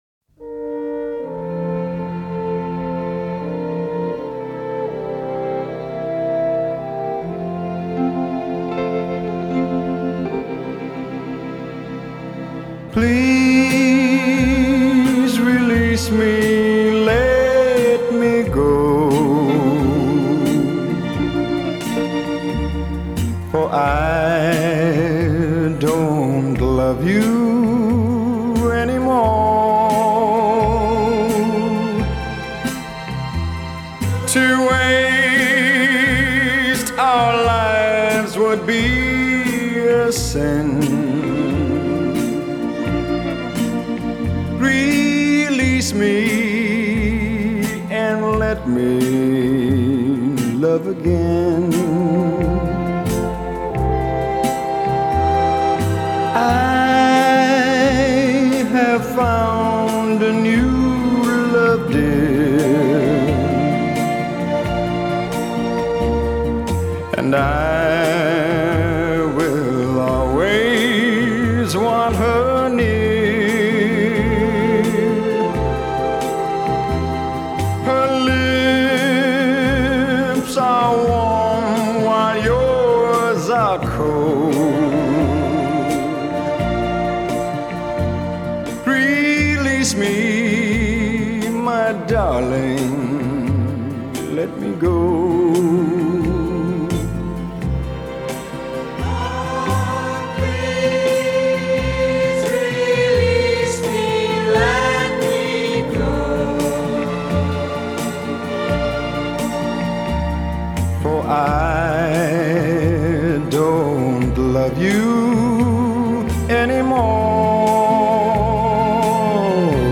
Traditional Pop، Easy Listening